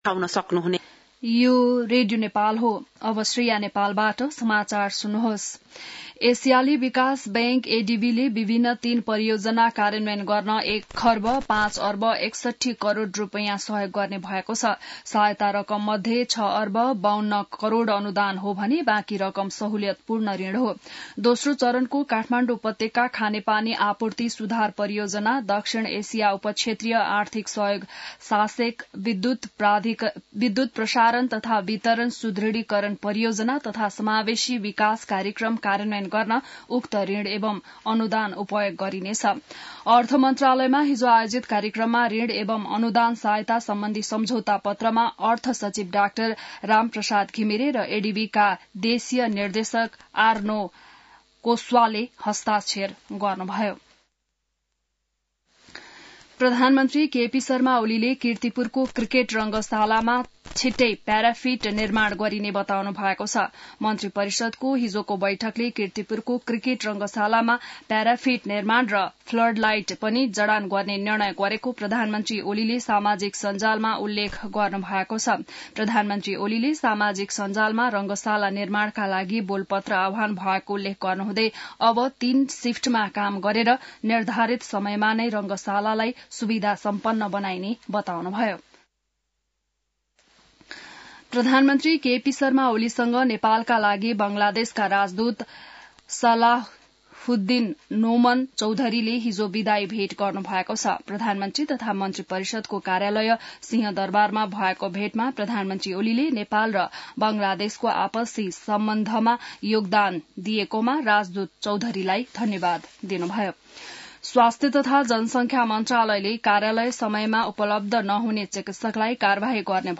बिहान ६ बजेको नेपाली समाचार : ७ पुष , २०८१